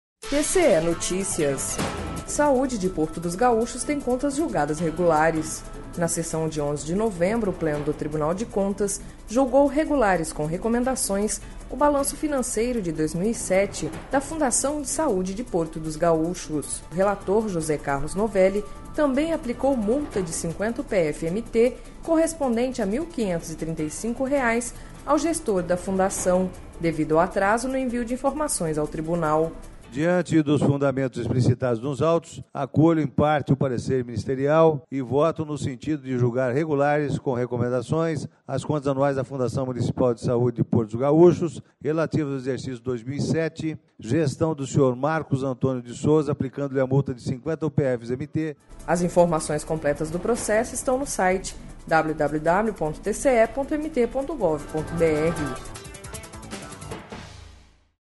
Sonora: José Carlos Novelli – conselheiro TCE-MT